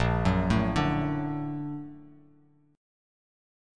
Bb+M7 Chord
Listen to Bb+M7 strummed